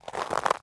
Ice Footstep 20.ogg